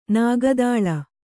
♪ nāga dāḷa